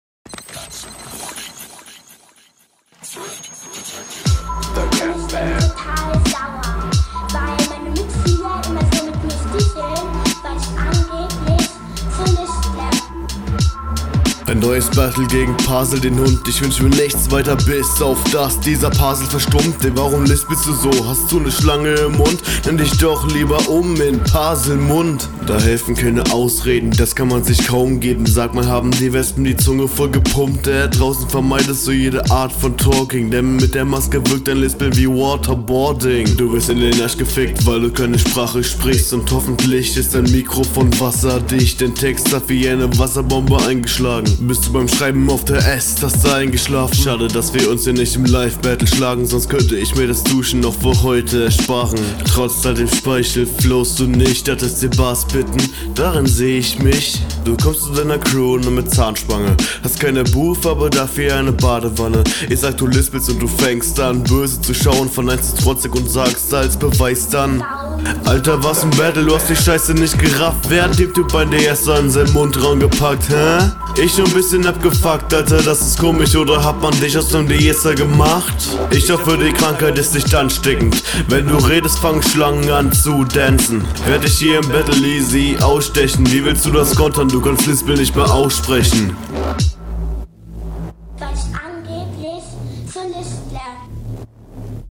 Battle Runden
Stimme ist clean aber zu laut auf dem Beat abgemischt.